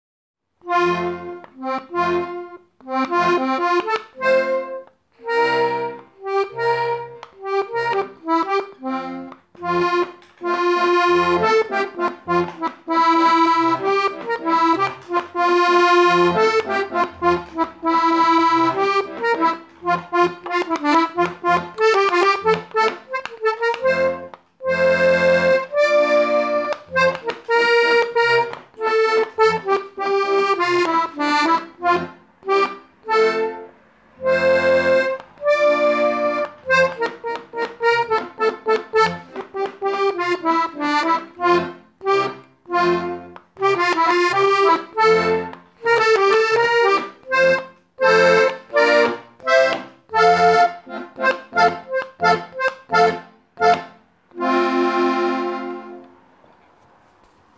Stark reduziert. Minimalistische Noten. Sehr verlangsamt.